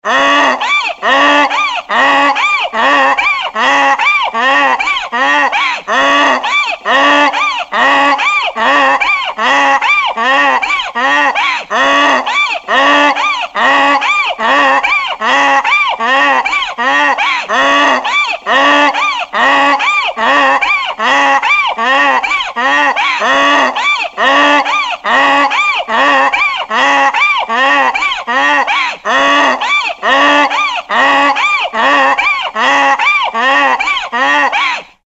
جلوه های صوتی
دانلود صدای الاغ در حال عرعر کردن از ساعد نیوز با لینک مستقیم و کیفیت بالا